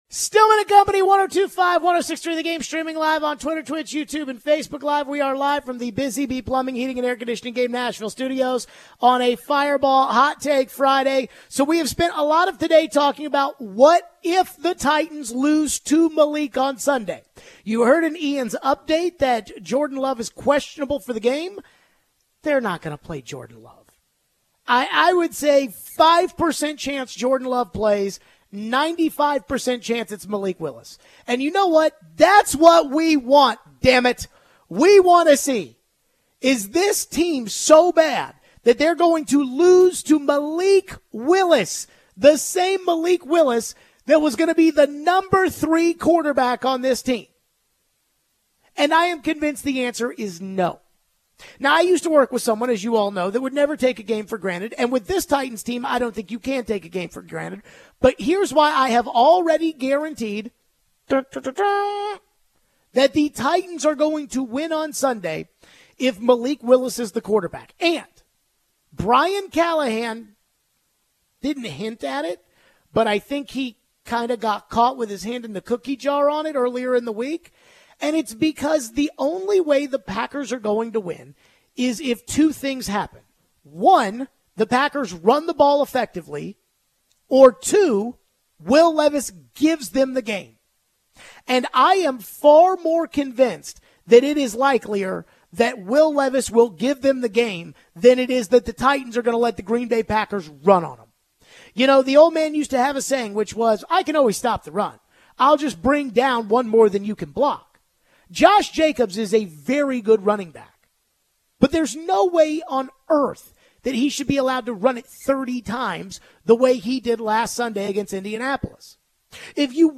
We get back into the organizational structure for the Titans and what Dianna Russini said about who is calling the shots for the Titans. We take more phones.